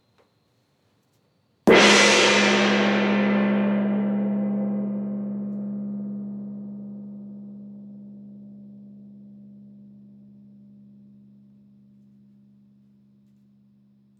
petit-tres-fort-haut.wav